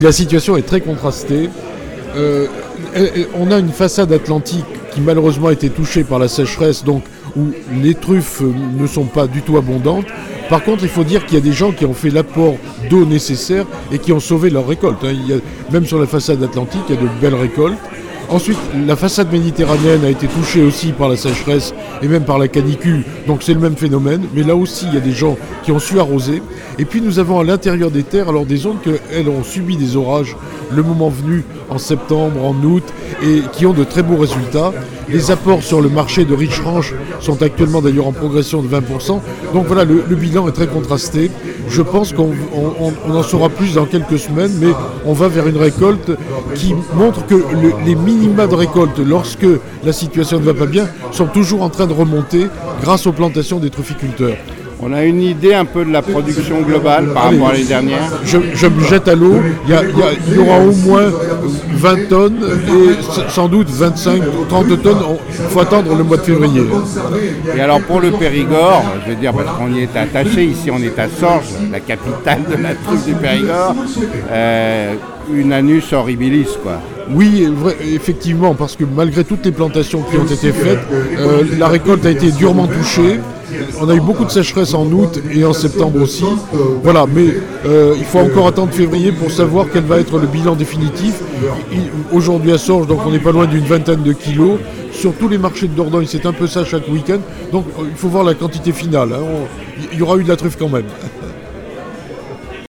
à la Fête de la truffe de Sorges en Dordogne